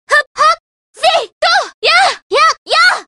Play, download and share Kasumi hit 8 original sound button!!!!
kasumi-hit-8.mp3